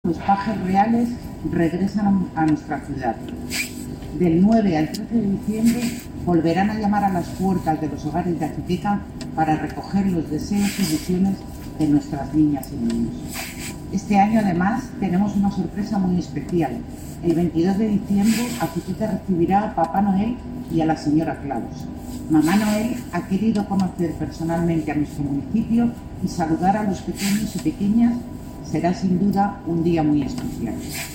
Declaraciones de la concejala de Fiestas sobre la visita de Pajes Reales y Papá y Mamá Noel